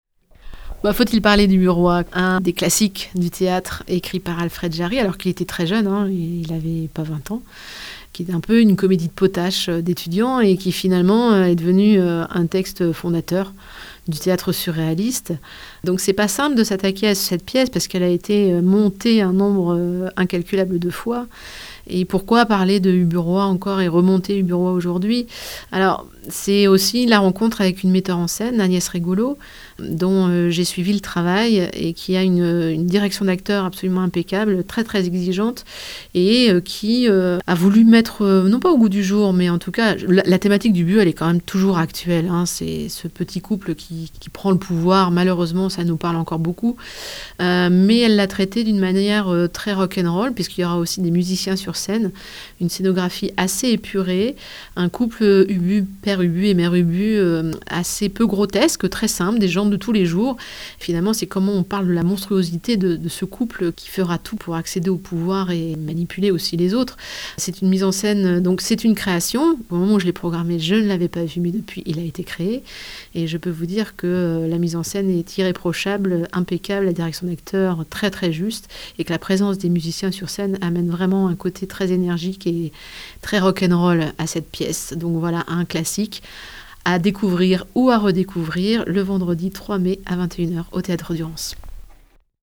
présente le spectacle au micro